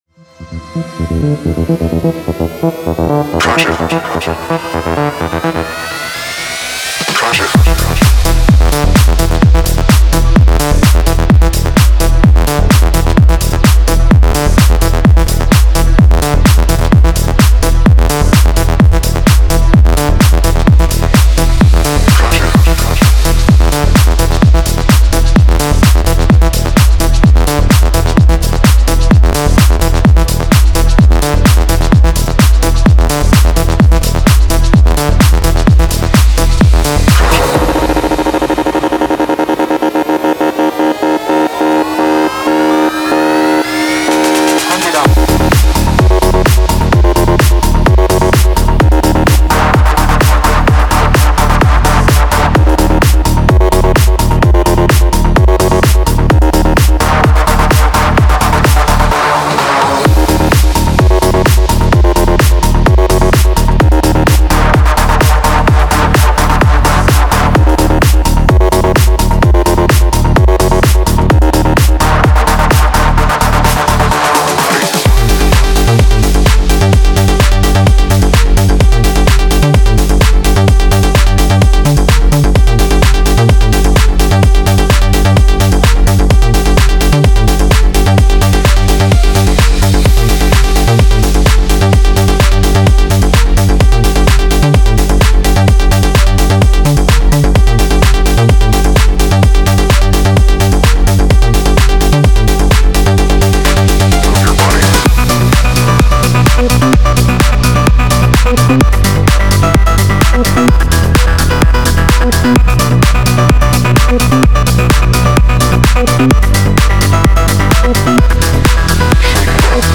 デモサウンドはコチラ↓
Genre:Tech House
30 Full Drum Loops 128 Bpm
20 Synth Shots In C